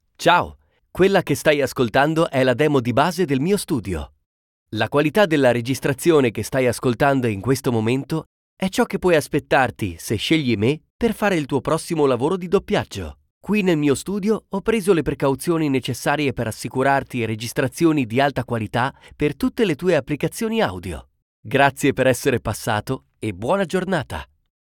Male
Authoritative, Confident, Conversational, Energetic, Friendly, Natural, Upbeat, Warm, Young
Audiobook, Commercial, Corporate, Documentary, Educational, E-Learning, Explainer, IVR or Phone Messaging, Narration
Microphone: Neumann U87
Audio equipment: Neumann U87 / AVALON Vt 737 sp / audio interface SSL 2 +